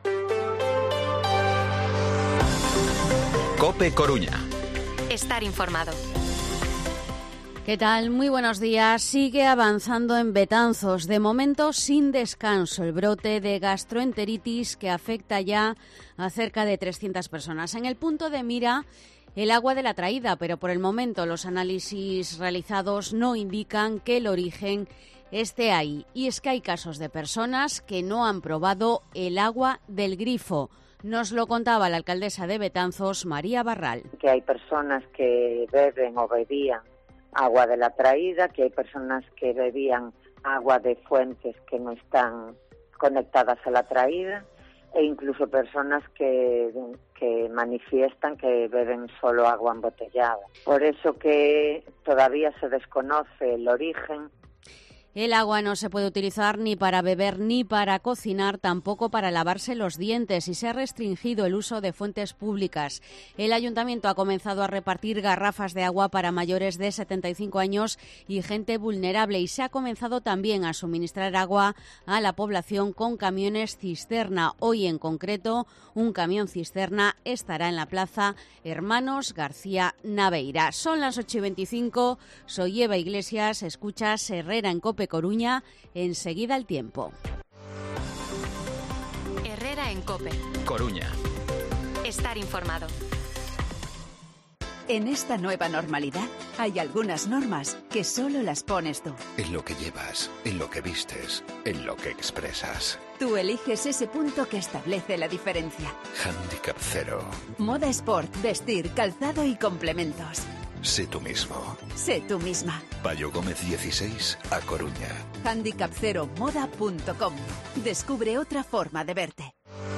Informativo COPE Coruña jueves, 1 de junio de 2023